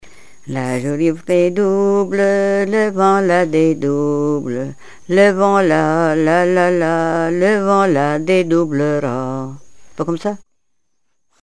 Couplets à danser
branle : courante, maraîchine
Répertoire de chansons traditionnelles et populaires
Pièce musicale inédite